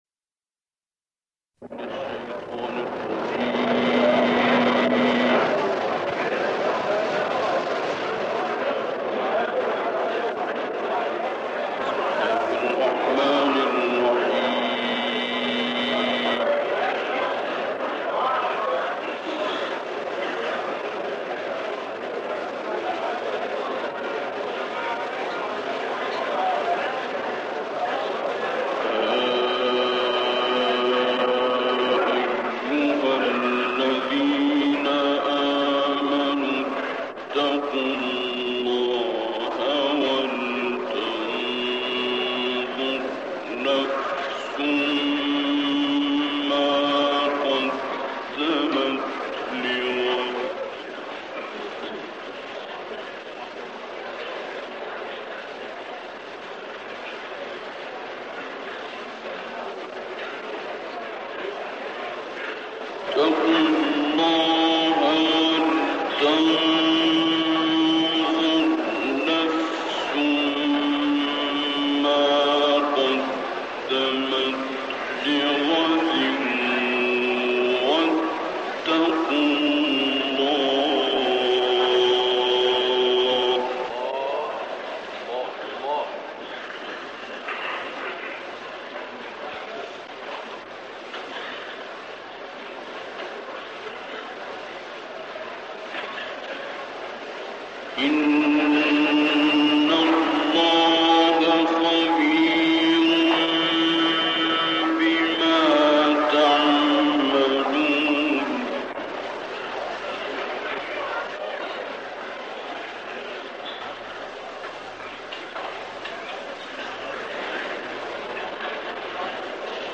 تلاوت بسیار زیبای استاد عبدالباسط، اجرا شده در کاظمین
تلاوت مجلسی